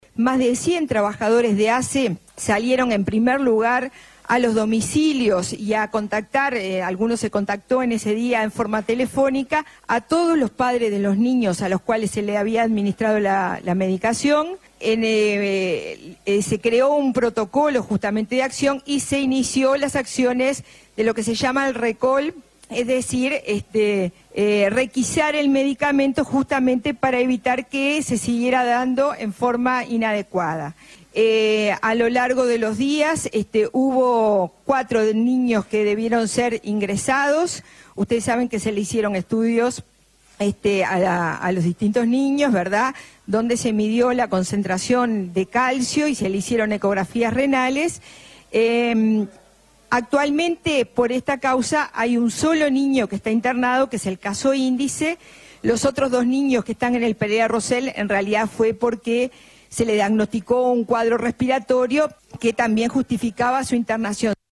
En la conferencia de prensa del pasado lunes, la presidenta de ASSE, Susana Muñiz, repasó cómo actuó el organismo una vez confirmados los hechos: